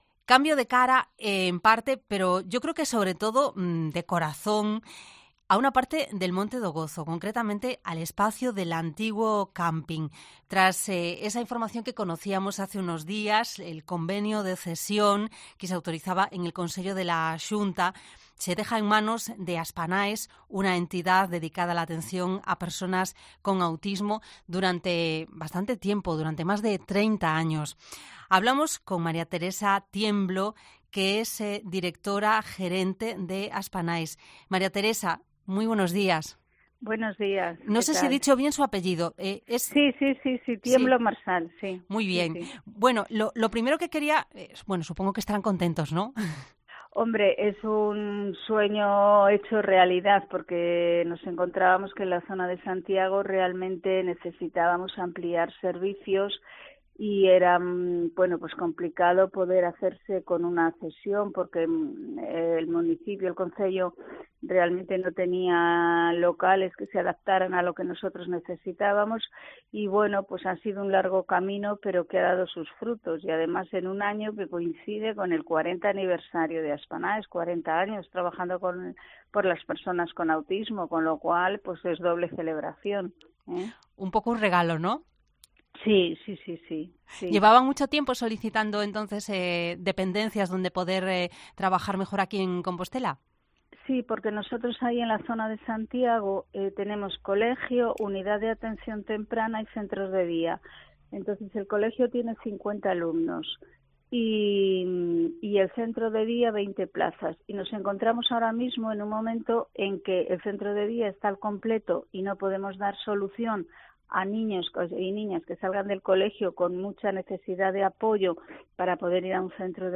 en una entrevista en Cope Santiago.